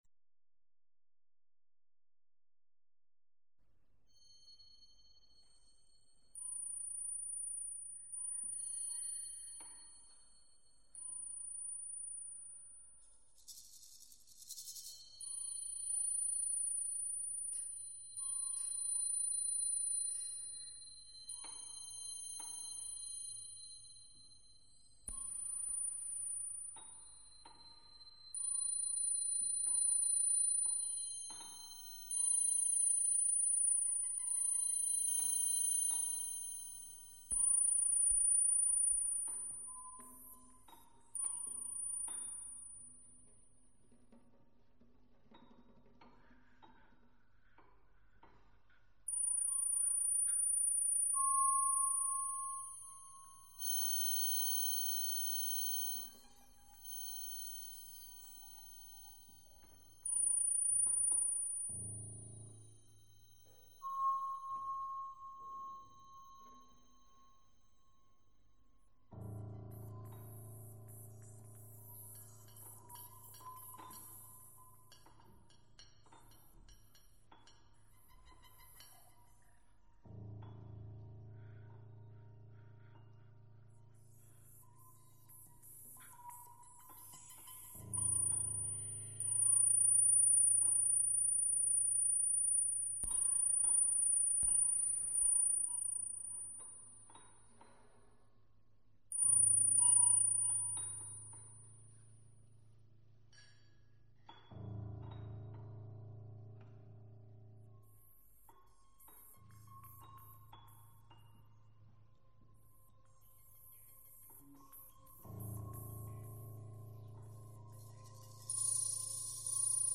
Pezzo per strumenti elettronici e percussioni acustiche.